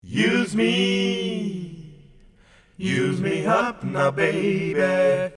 Les choeurs sur 2ème tps